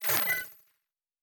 pgs/Assets/Audio/Sci-Fi Sounds/Electric/Device 3 Start.wav at 7452e70b8c5ad2f7daae623e1a952eb18c9caab4